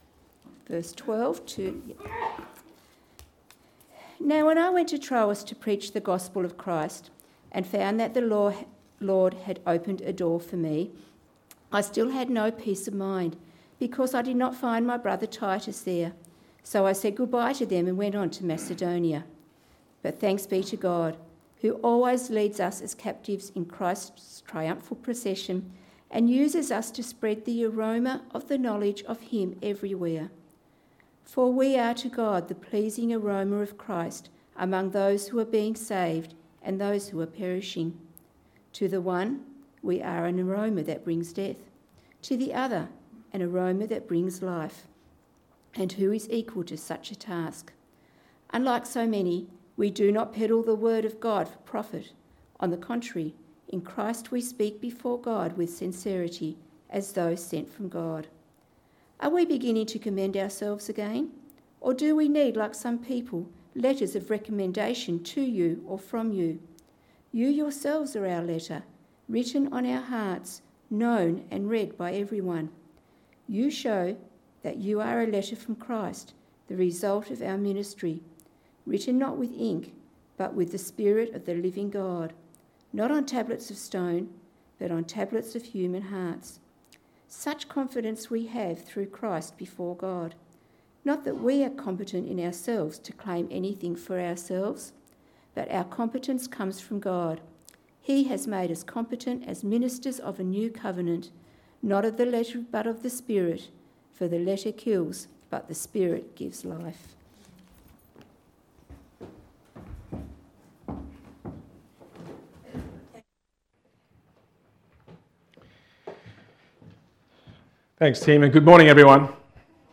Text: 2 Corinthians 2: 12-3: 6 Sermon